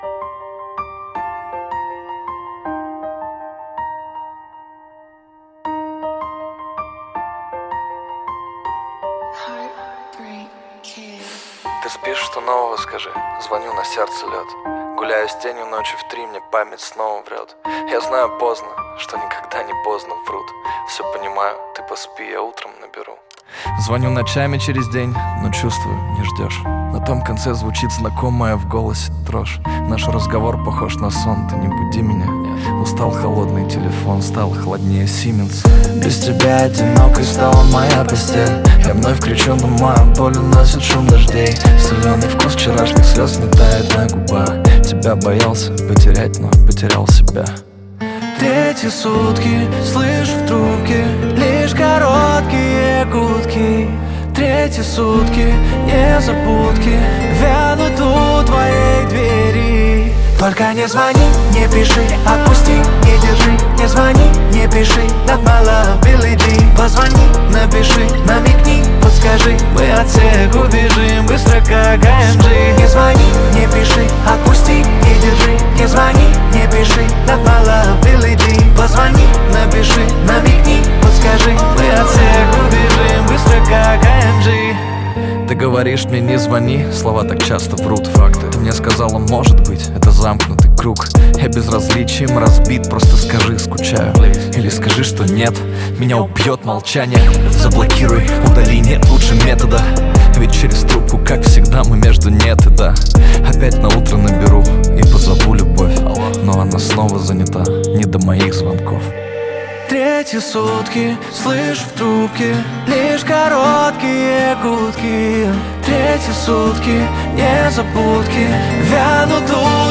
Качество: 320 kbps, stereo
Поп музыка, Русские поп песни